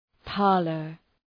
{‘pɑ:rlər}